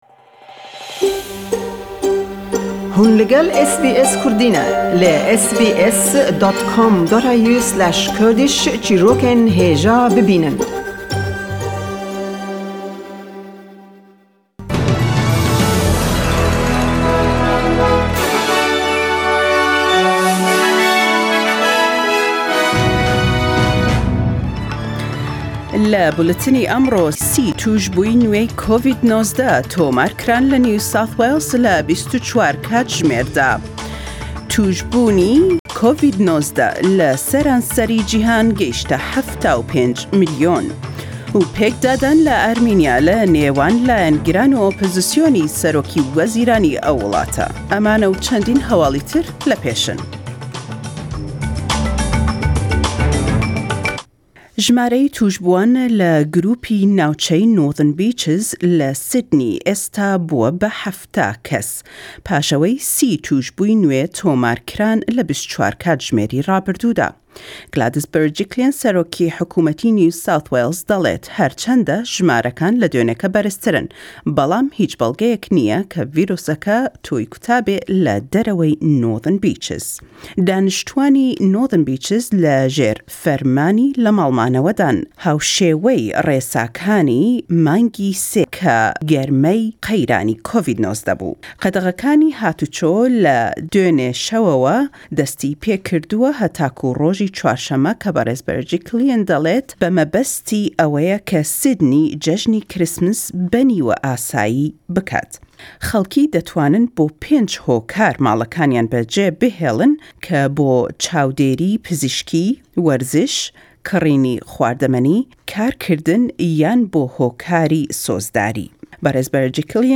Weekend News 20 December 2020